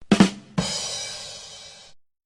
Platillos Sound Button - Free Download & Play